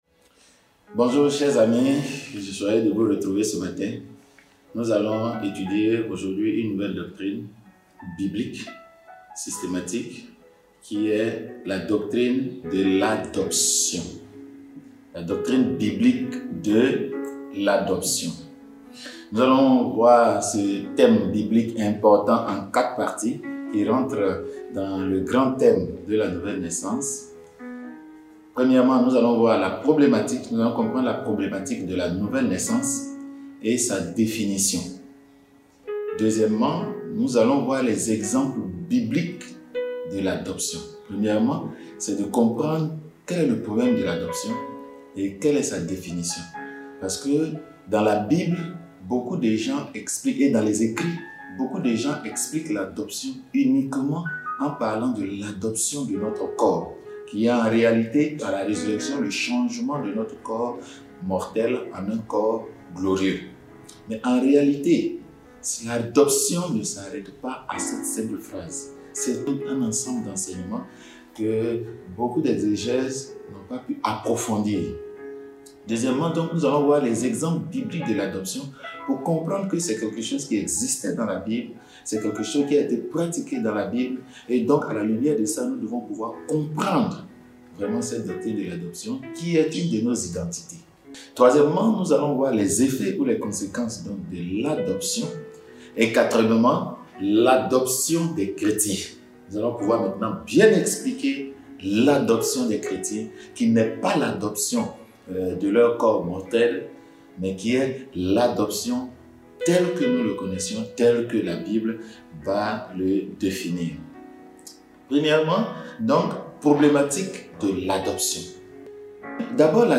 Message sur l’adoption 1: DÉFINITION ET PRINCIPE